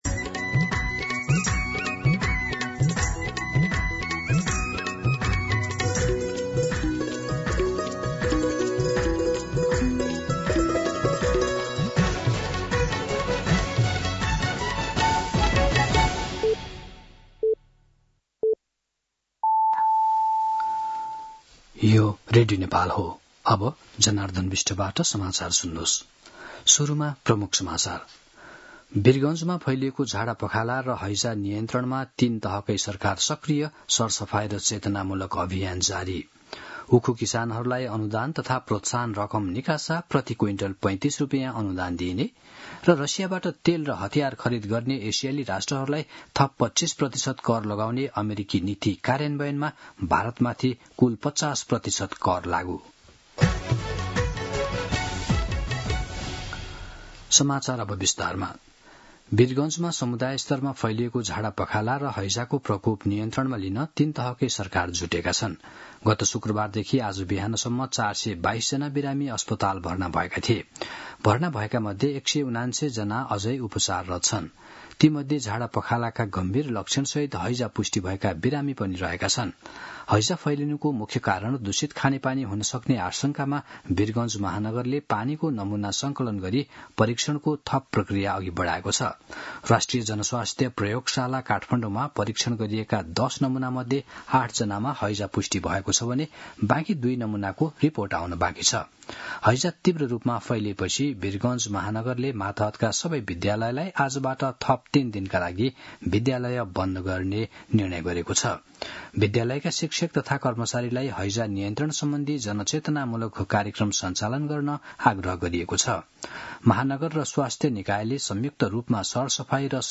दिउँसो ३ बजेको नेपाली समाचार : ११ भदौ , २०८२
3pm-News-05-11.mp3